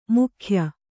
♪ mukhya